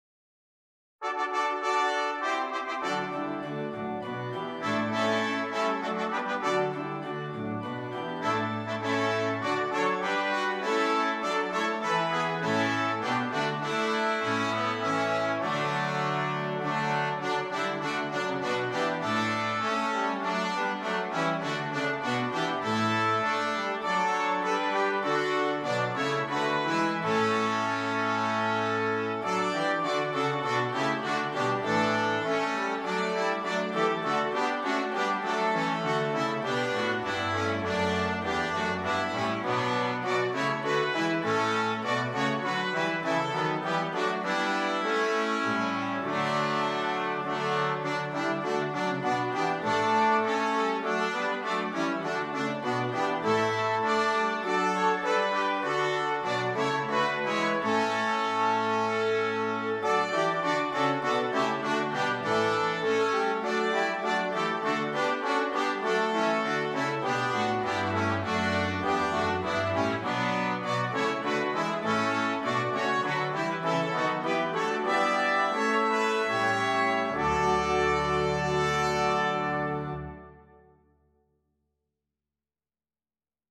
• Brass Quartet and Organ